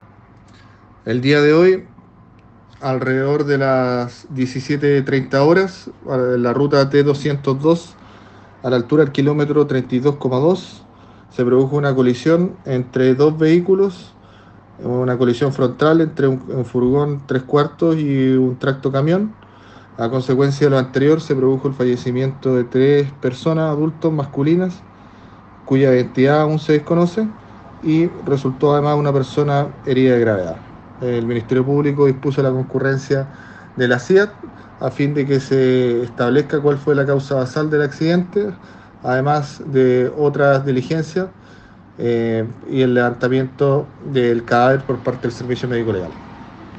El fiscal Pierre Neira se refirió al  accidente en Valdivia en el que fallecieron tres hombres, incluidos los conductores de los vehículos que colisionaron frontalmente.